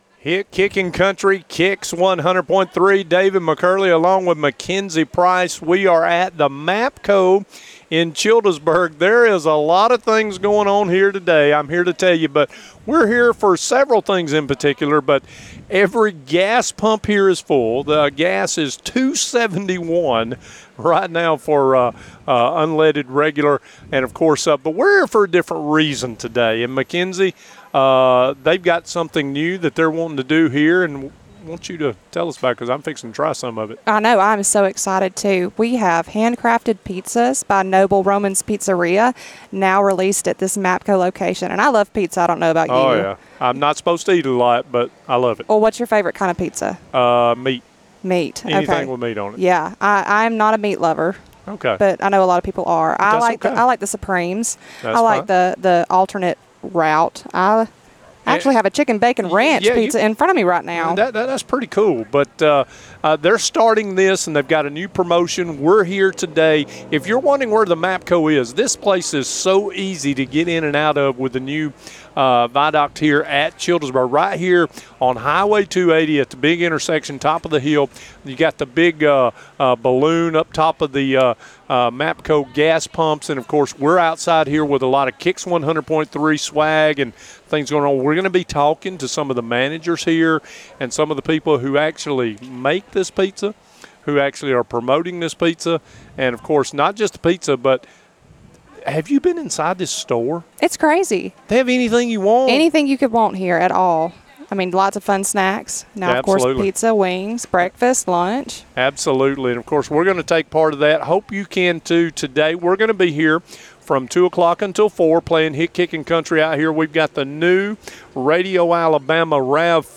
Live from the MAPCO in Childersburg